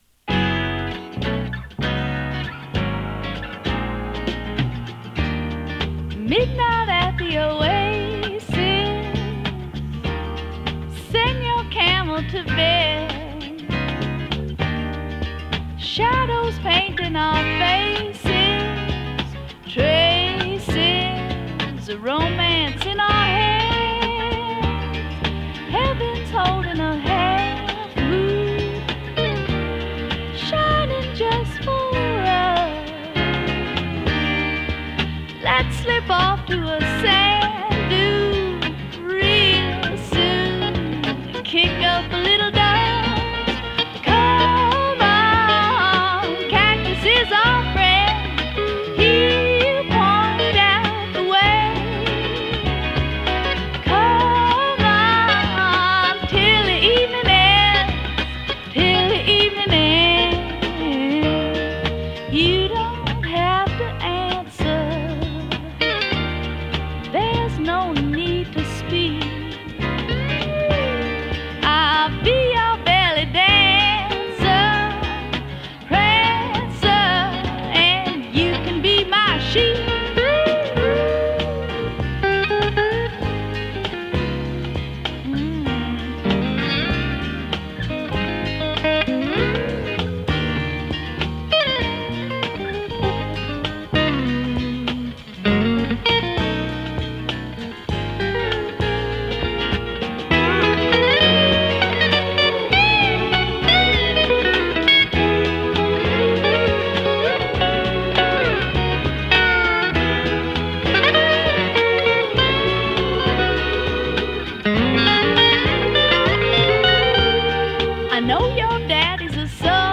メロウなサウンドに可憐な唄声が映える